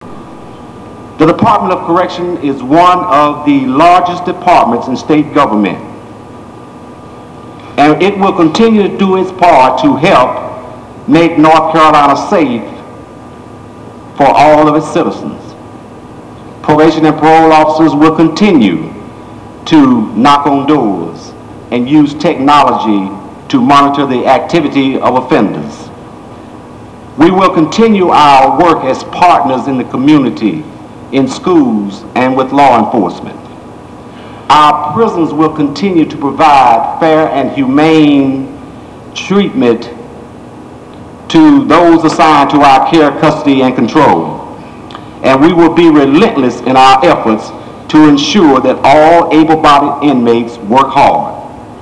RALEIGH – Gov. Jim Hunt, legislators, Cabinet and Council of State members, prison superintendents and correctional officers from across the state were among the host of hundreds that filled the Old House Chamber today as Theodis Beck was sworn in as the new Secretary of Correction.
Secretary Beck's Speech